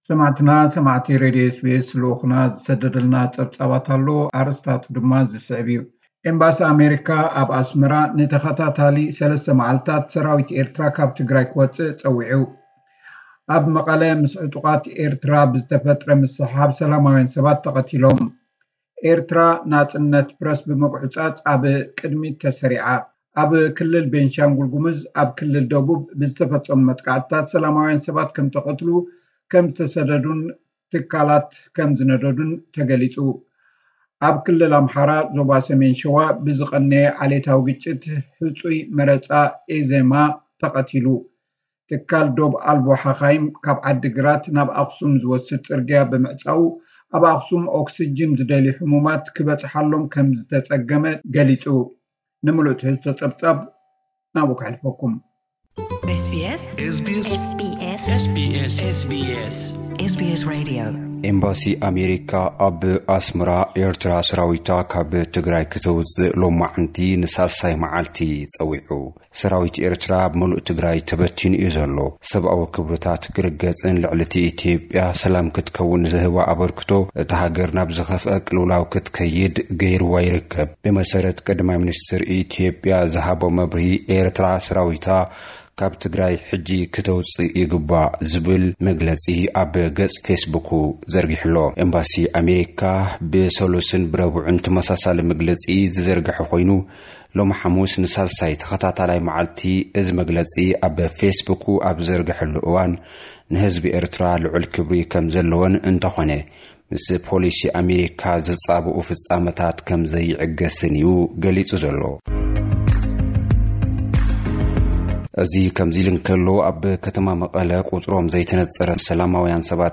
ሰማዕቲና ሰማዕቲ ሬድዮ ኤስ ቢ ኤስ፡ ልኡኽና ዝሰደደልና ጸብጻብ ኣሎ ኣርእስታቱ ድማ እዚ ዝስዕብዩ ኣብ መቐለ ምስ ዕጡቓት ኤርትራ ብዝተፈጥረ ምስሕሓብ ሰላማውያን ሰባት ተቐቲሎም። ኤርትራዊ ናጽነት ፕሬስ ብምጉዕጻጽ ኣብ ቅድሚት ተሰሪዓ።